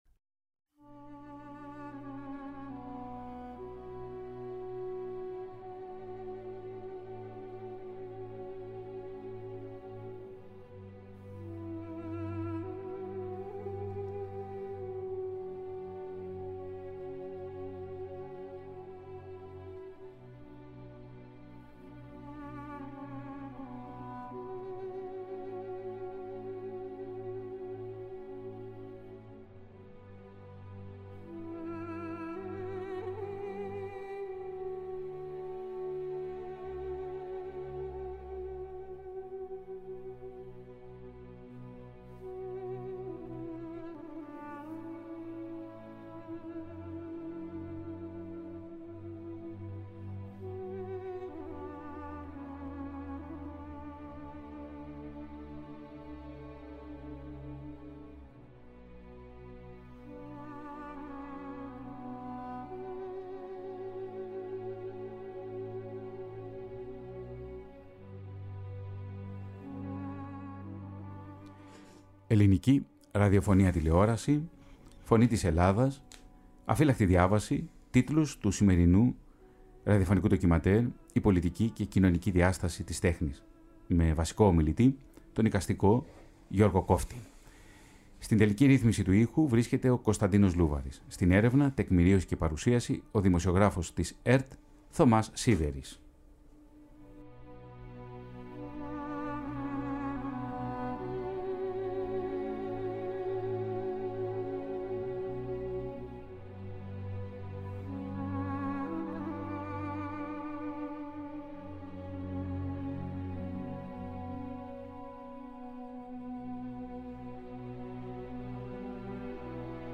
στον ραδιοθάλαμο της Φωνής της Ελλάδας